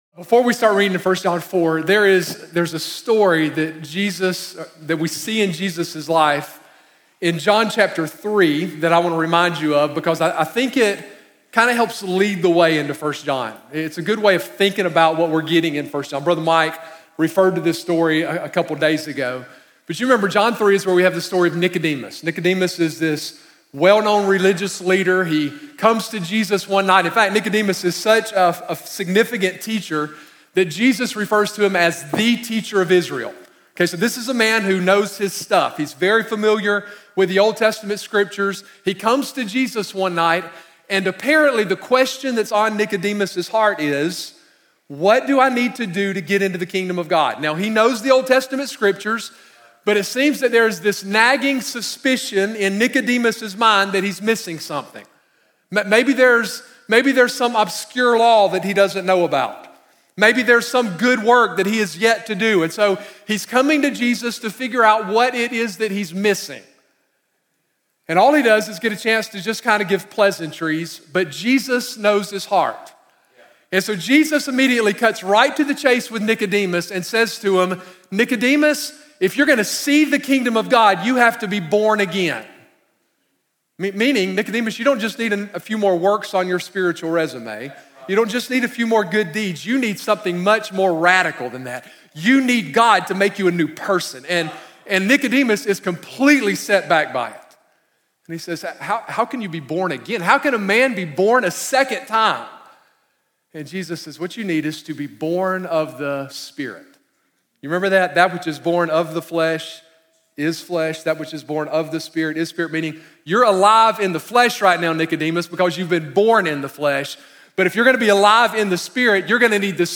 Message #08 from the ESM Summer Camp sermon series through the book of First John entitled "You Can Know"